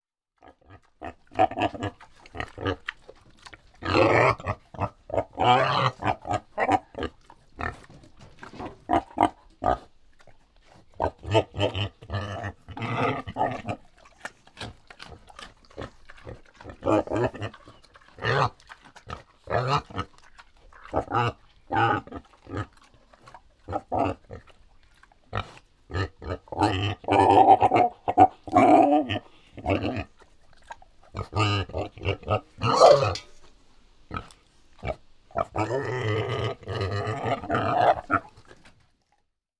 На этой странице собраны натуральные звуки диких кабанов: от хрюканья и рычания до топота копыт по лесу.
Какой звук издает кабан среднего размера